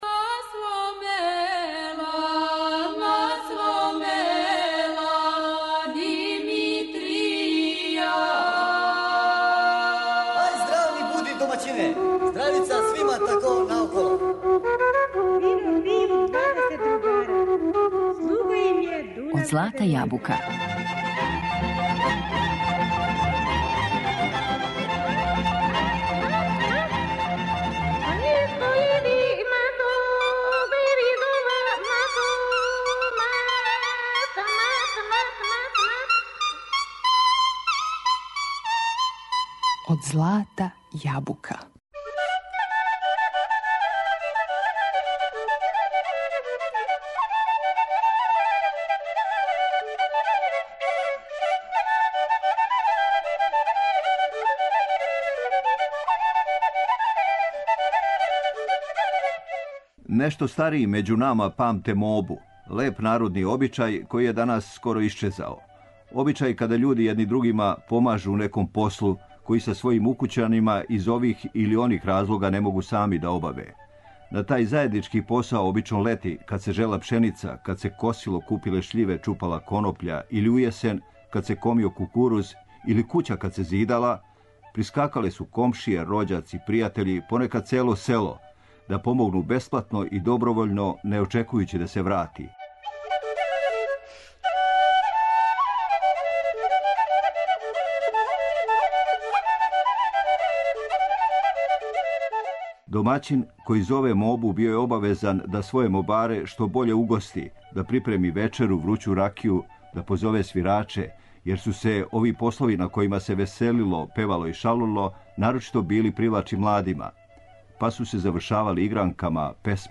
Емисија изворне народне музике